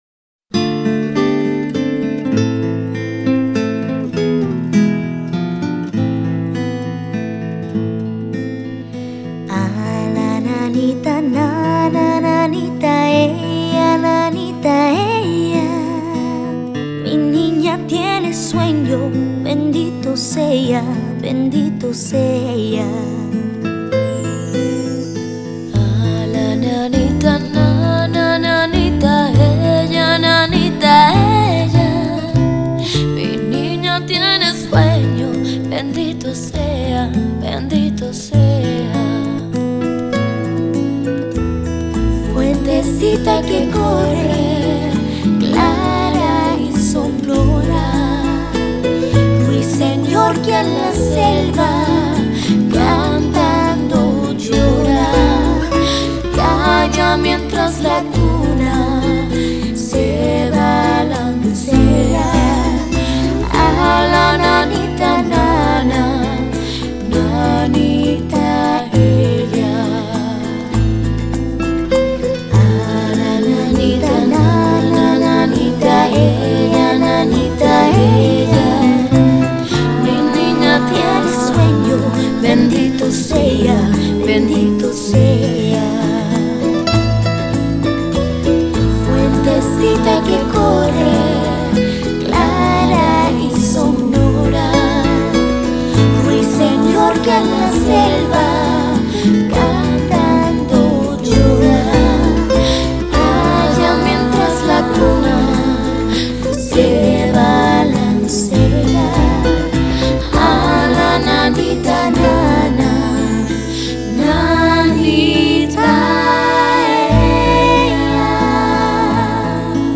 一首好听的催眠曲 简单的旋律
温柔、安详、宁静 一种无与伦比的静逸的美 这是一首西文的圣诞歌曲